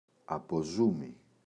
αποζούμι, το [apo’zumi]